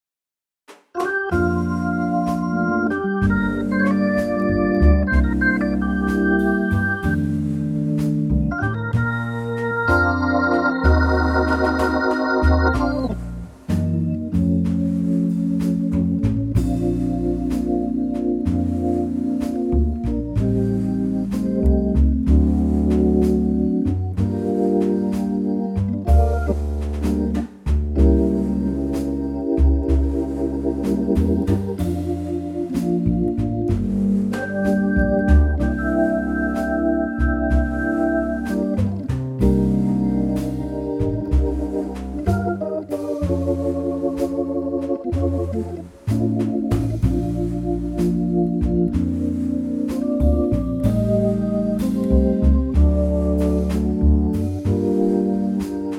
key - Bb
an organ trio arrangement and a lovely ballad tempo.